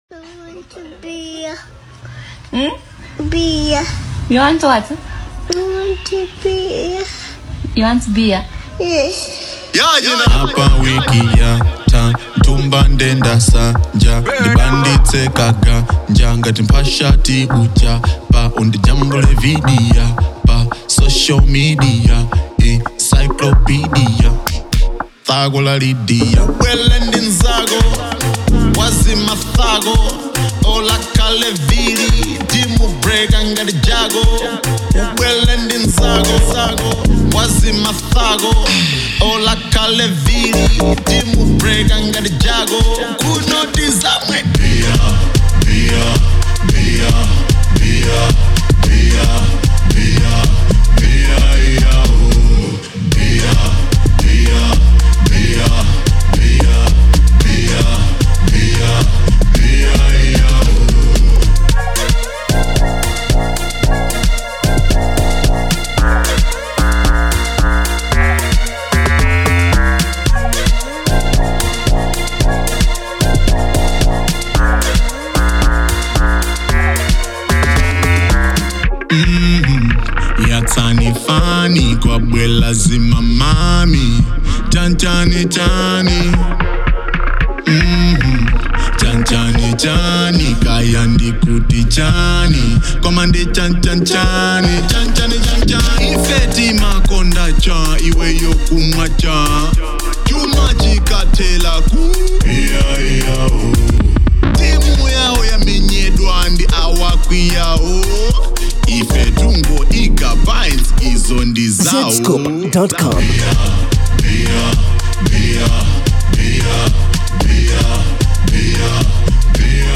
a certified club banger!